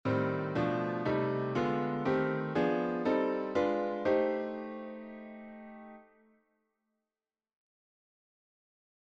Improvisation Piano Jazz
Accord iim7b5